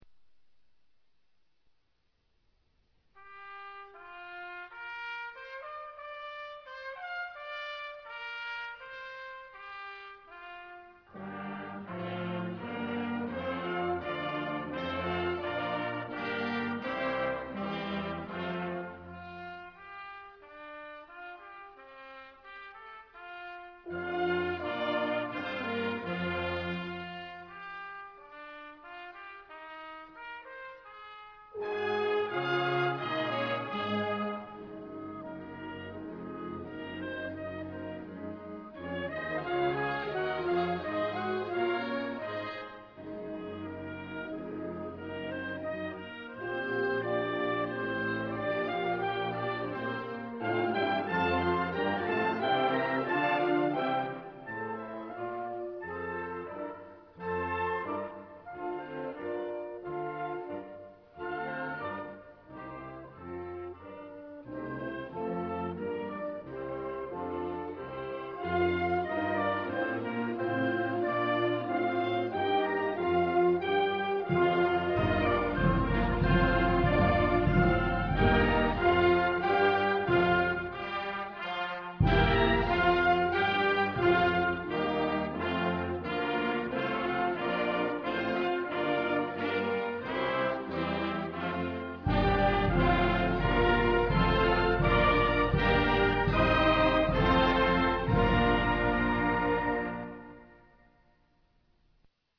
地點: 音乾乾的中山堂